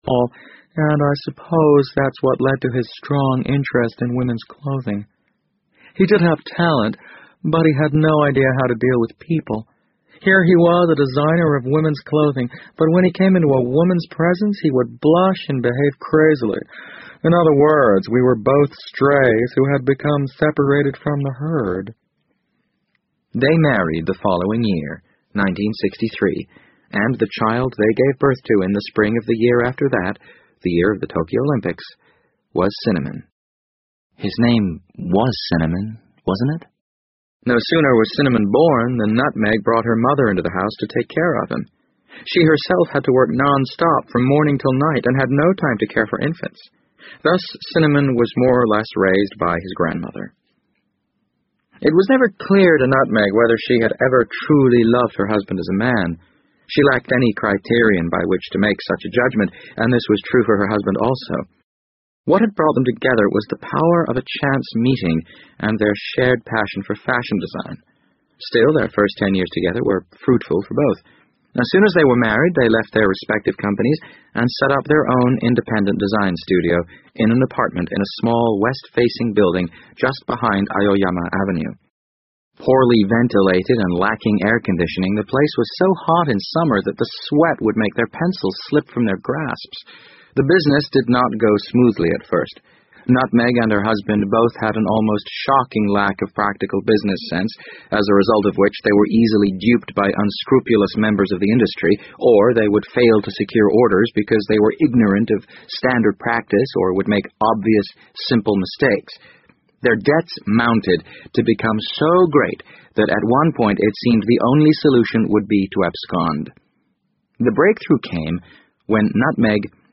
BBC英文广播剧在线听 The Wind Up Bird 012 - 10 听力文件下载—在线英语听力室